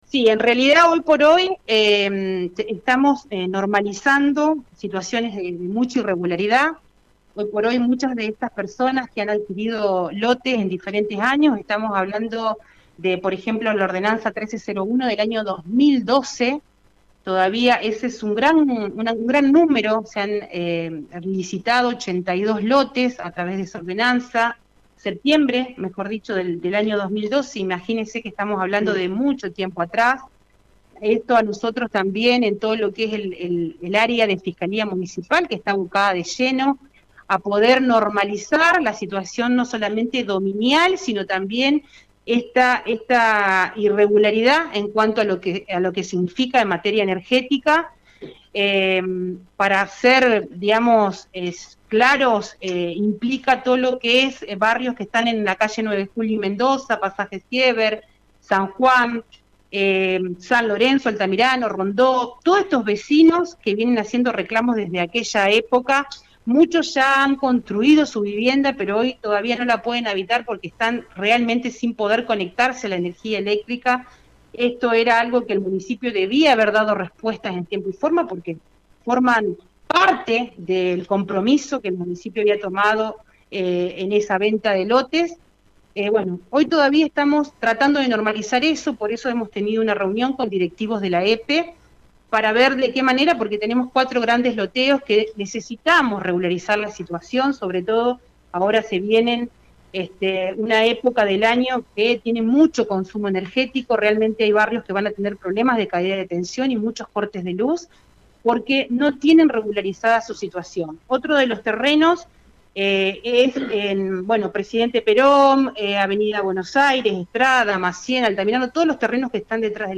En una entrevista que realizó Radio Eme Ceres habló con la Intendente Alejandra Dupouy y la Fiscal Municipal Yanina Brondoni para conocer la situación de muchos loteos en los que se encontraron irregularidades.